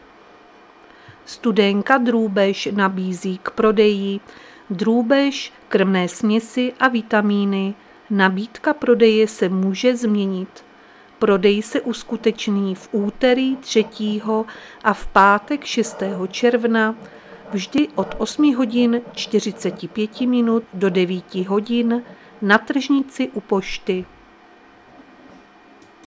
Záznam hlášení místního rozhlasu 2.6.2025